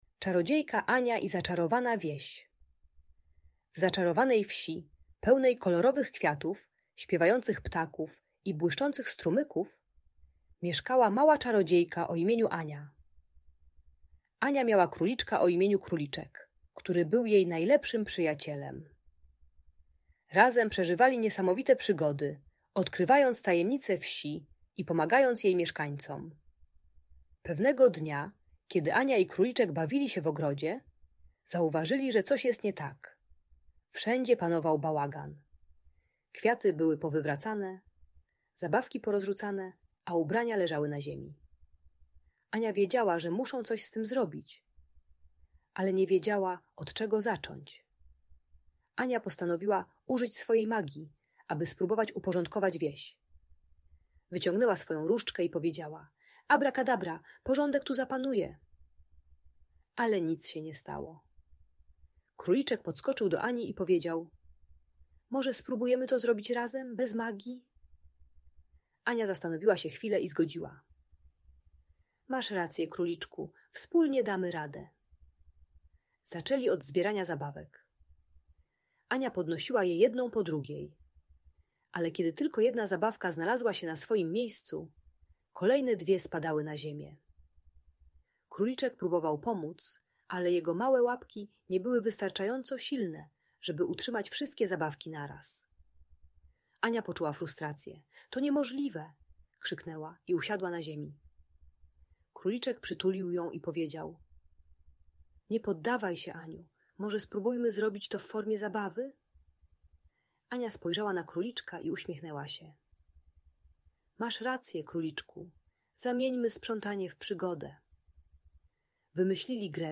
Historia Ani i Króliczka - Audiobajka